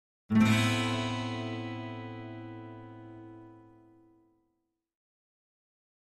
Acoustic Guitar - Minor Chord 5 - Thin Septa Chord (7)